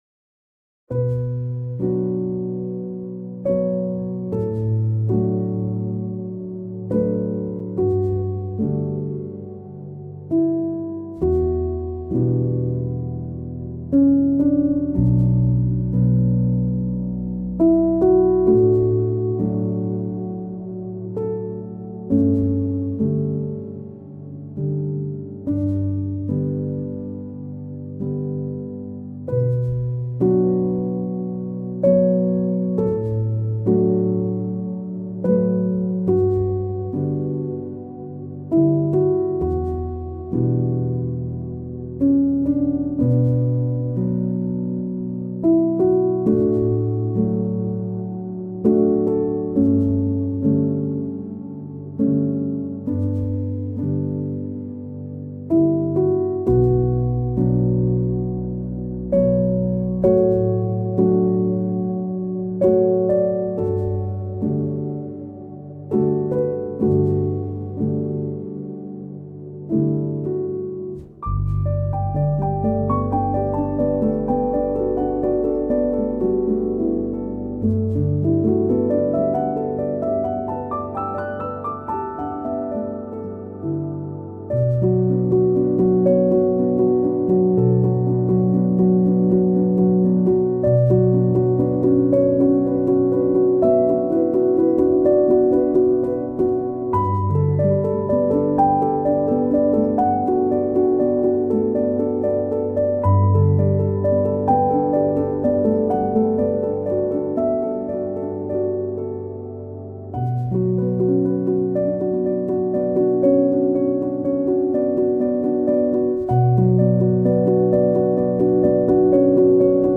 موسیقی کنار تو
سبک آرامش بخش , پیانو , عصر جدید , موسیقی بی کلام
موسیقی بی کلام نیو ایج